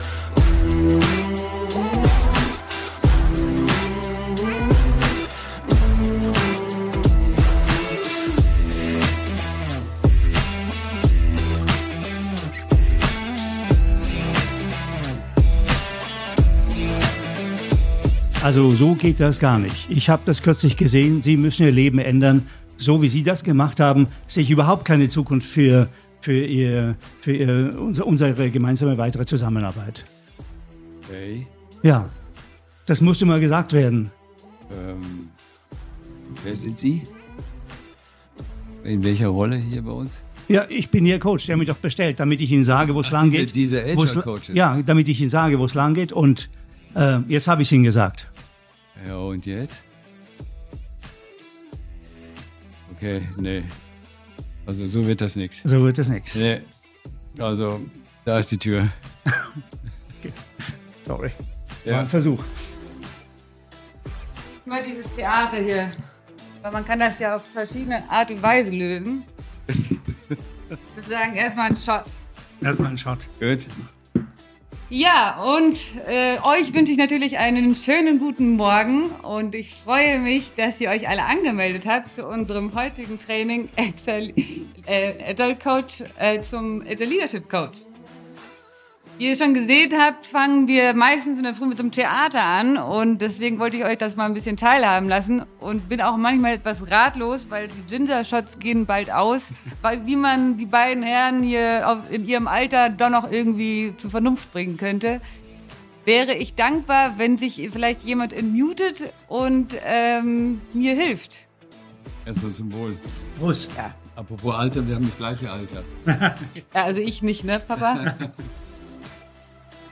Sie demonstrieren live in einem Rollenspiel wie du eine Führungskraft für dich gewinnen kannst und zur Selbstreflektion anleitest....